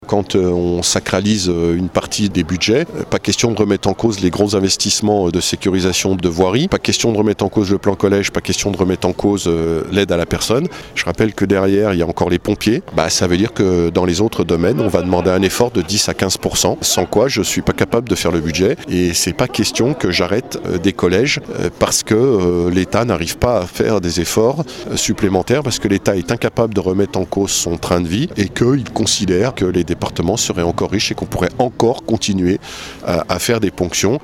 Martial Saddier, Président du Conseil départemental de la Haute-Savoie :